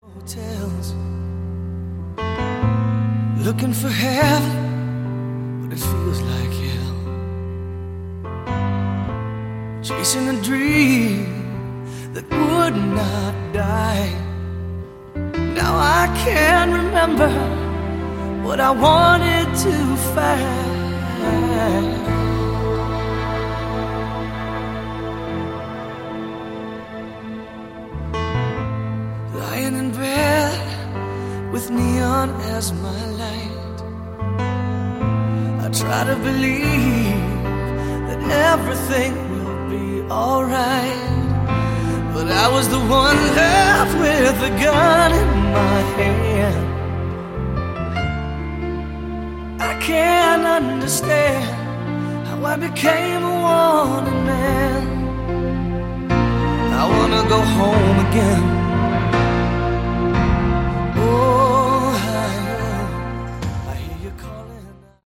Category: AOR
vocals
keyboards
guitar
drums
bass